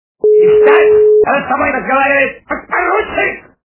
» Звуки » Из фильмов и телепередач » Белое солнце пустыни - Встать!..
При прослушивании Белое солнце пустыни - Встать!.. качество понижено и присутствуют гудки.